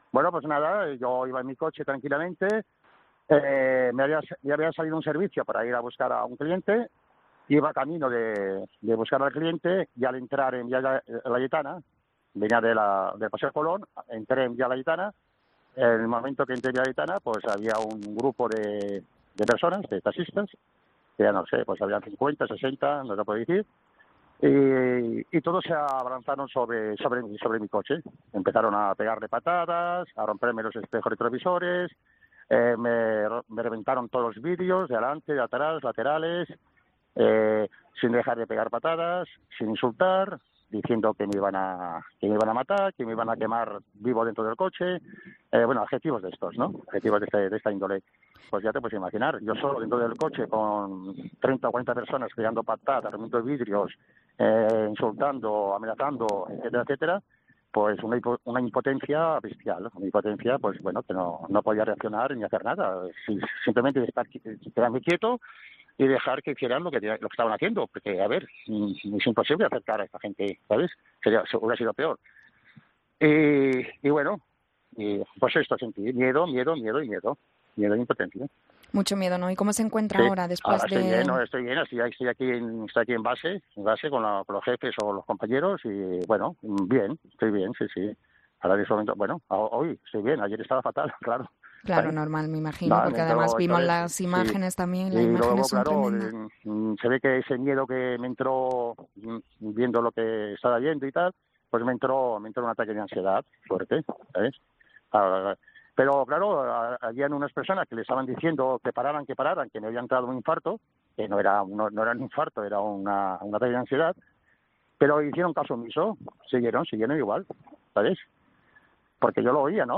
Cuando hemos hablado con él se encontraba en base, aún con el miedo en el cuerpo, dispuesto a emprender de nuevo el viaje.